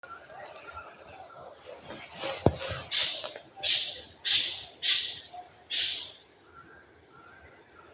Nombre científico: Alisterus scapularis
Nombre en inglés: Australian King Parrot
Localidad o área protegida: Lamington National Park
Condición: Silvestre
Certeza: Vocalización Grabada
australian-king-parrot.mp3